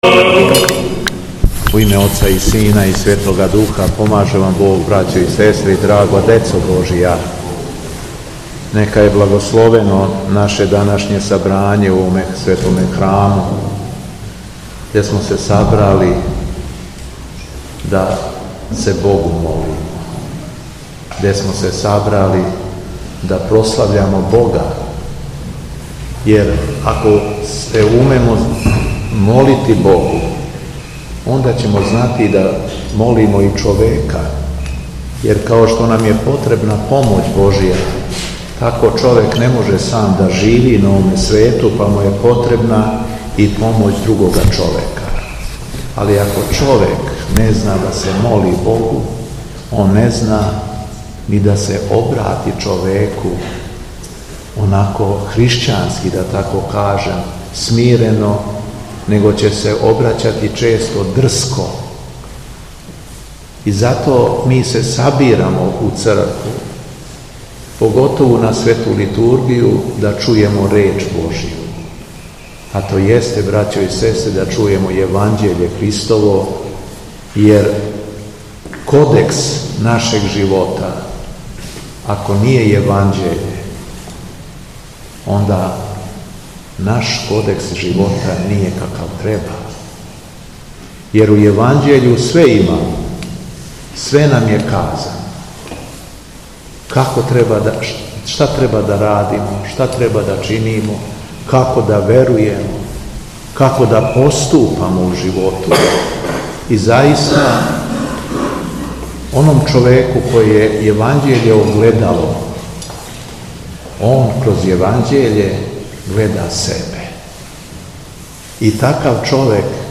Беседа Његовог Високопреосвештенства Митрополита шумадијског г. Јована
По прочитаном Јеванђељу Високопреосвећени митрополит Господин Јован се обратио верном народу пастирском беседом.